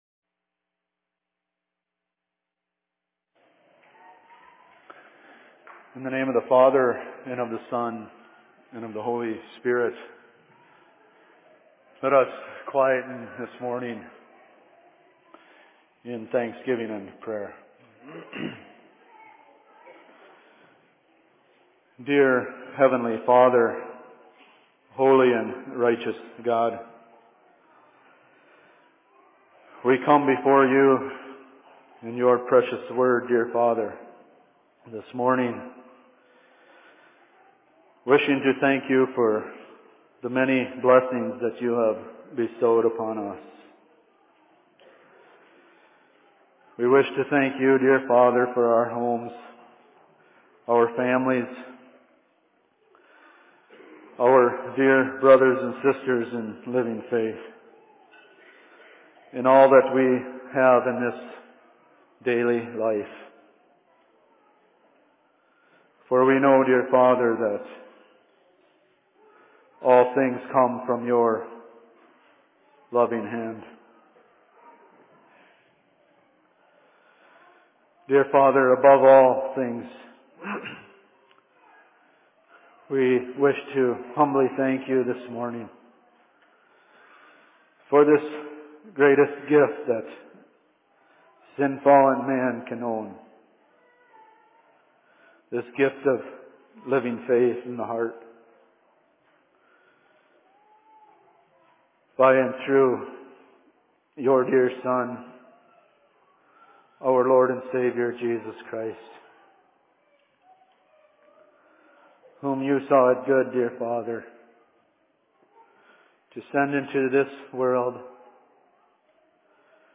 Sermon in Cokato 23.09.2012
Location: LLC Cokato